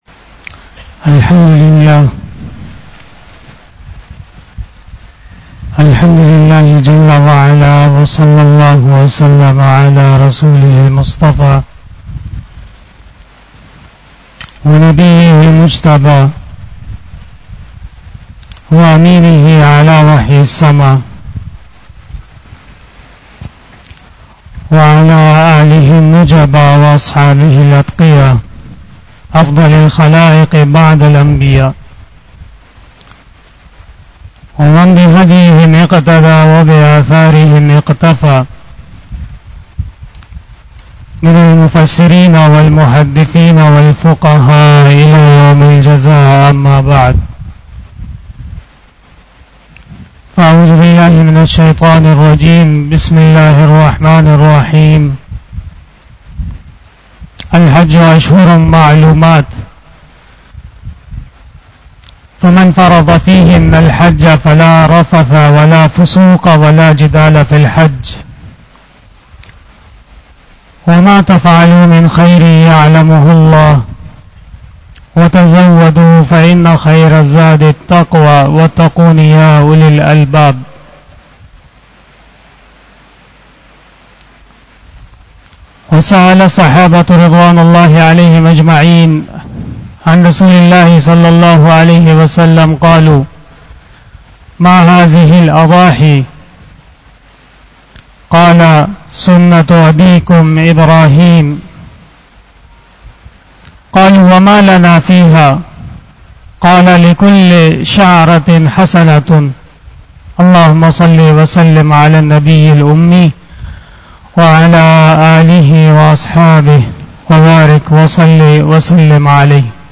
27 BAYAN E JUMA TUL MUBARAK 02 July 2021 (21 Zil Qadah 1442H)